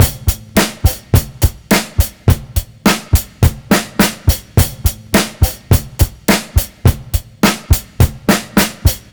RI BEAT 1 -L.wav